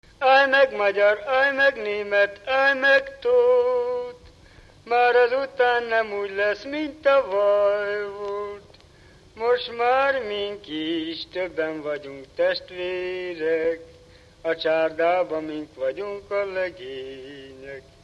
Alföld - Pest-Pilis-Solt-Kiskun vm. - Kecel
ének
Stílus: 1.1. Ereszkedő kvintváltó pentaton dallamok
Kadencia: 7 (5) b3 1